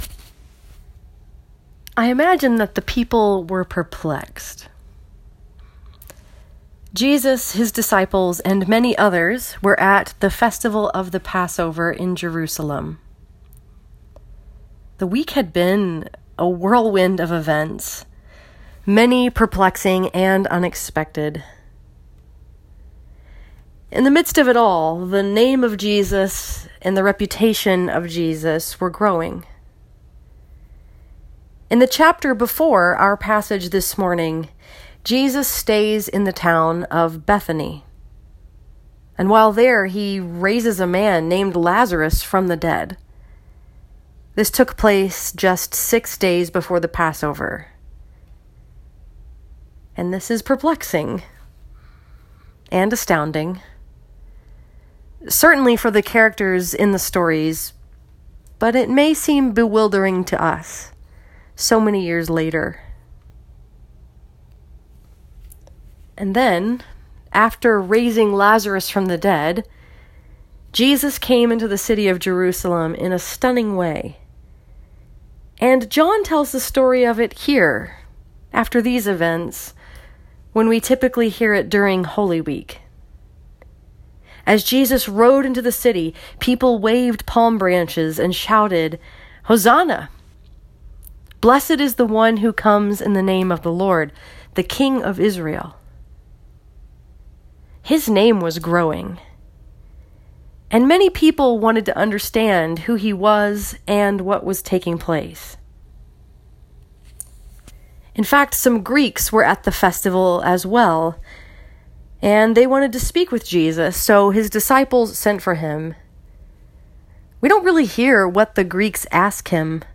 This sermon was preached at Starr Presbyterian Church in Royal Oak, Michigan and was focused upon the story that is told in John 12:20-36.